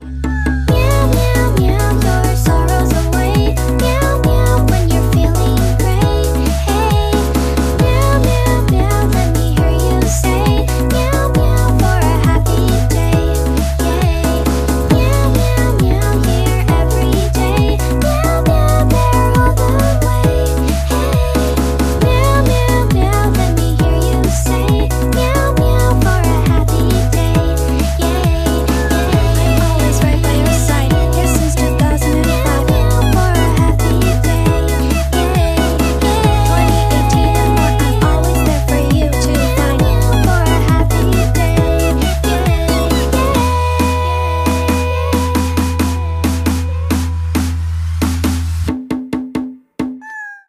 забавные
милые
Милый и позитивный рингтончик на ваш телефон.